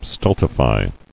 [stul·ti·fy]